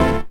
12SFX 03  -L.wav